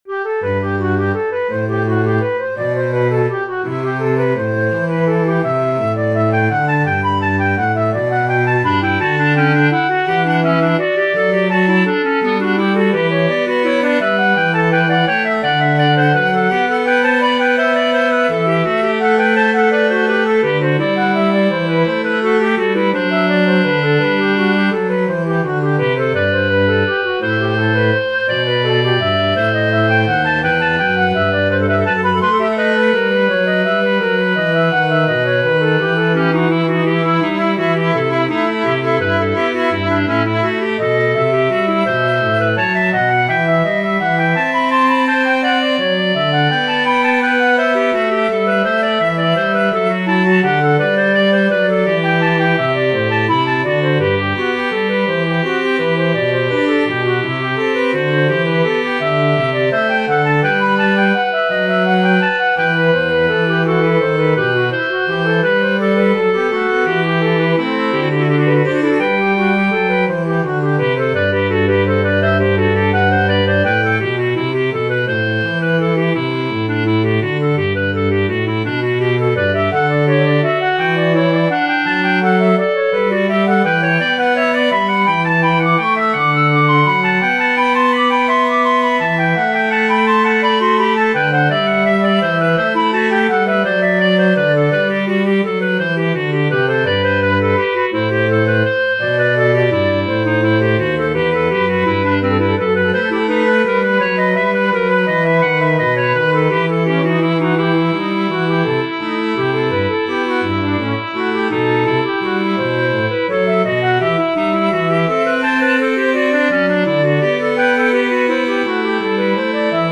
Arrangement pour flûte, hautbois (clarinette en Ut) et violoncelle de Mike Magatagan
Rendu MP3